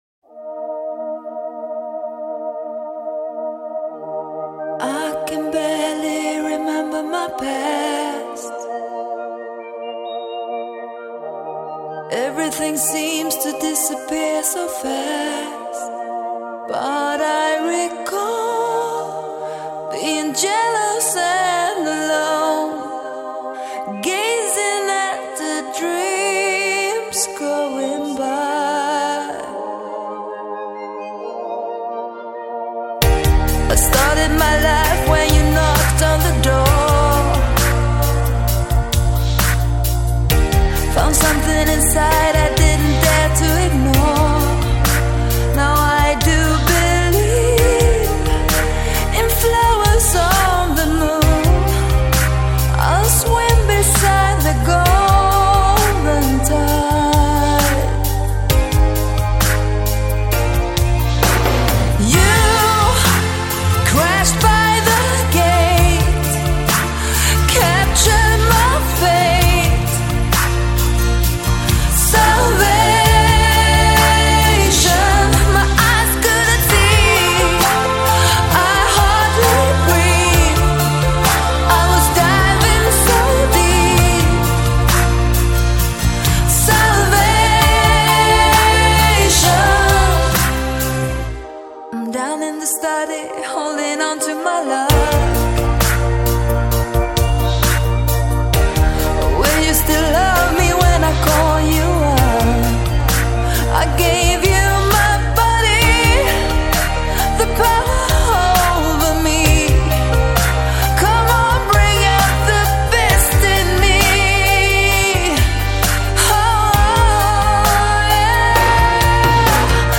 Жанр: Pop, Rock